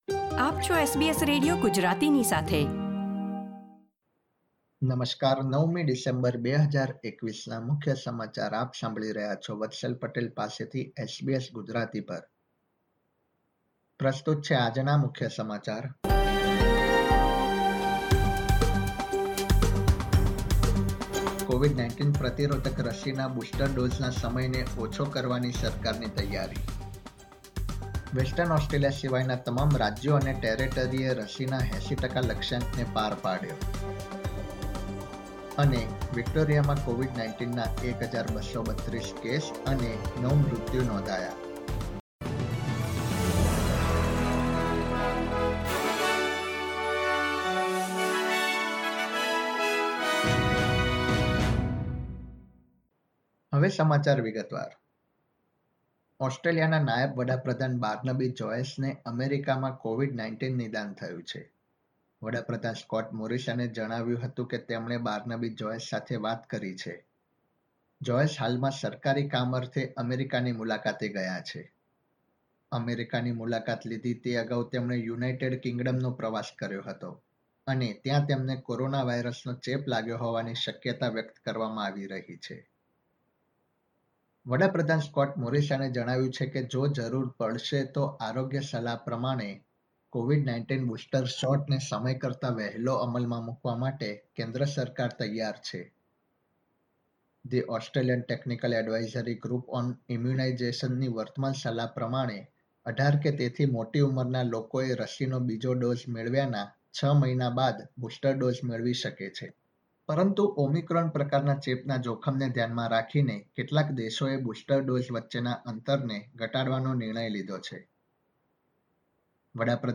SBS Gujarati News Bulletin 9 December 2021
gujarati_0912_newsbulletin.mp3